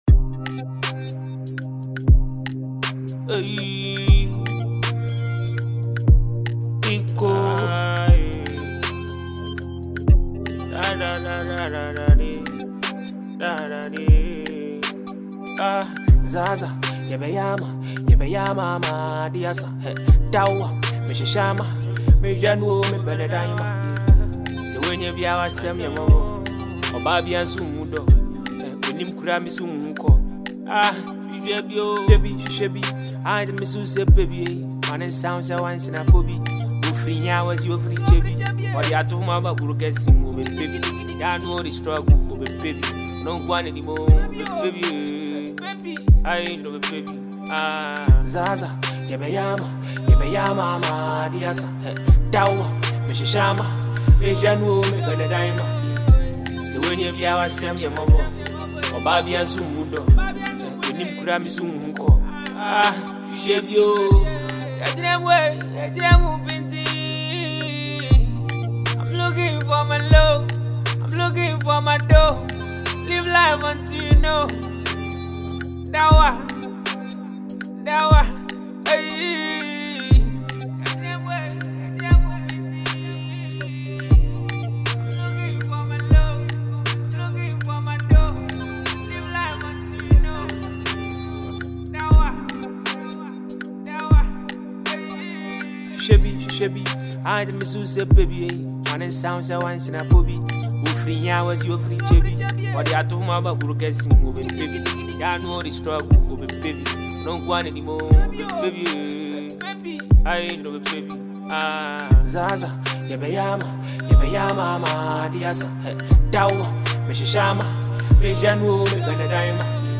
energetic and inspiring Ghanaian Afro-fusion record
Genre: Afrobeat / Afro-fusion